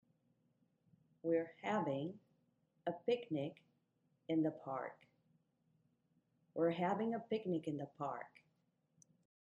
そして今回はピクニック、picnic
を一番大事な言葉として
一番強調します。